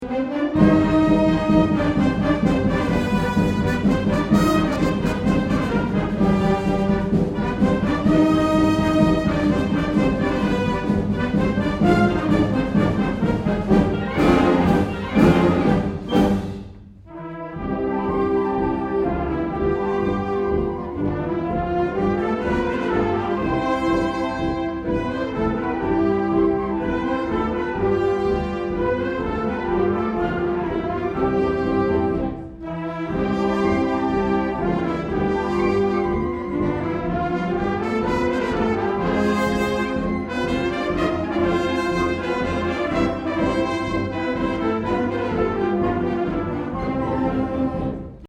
Below are music excerpts from some of our concerts.
2009 Summer Concert
June 14, 2009 - San Marcos High School